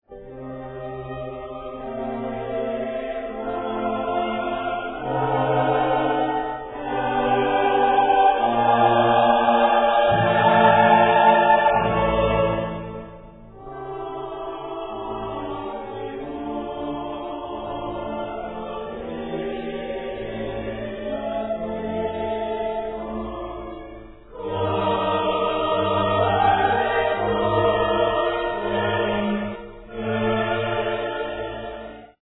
Solo Quartet, Chorus and Orchestra
11 CHORUS: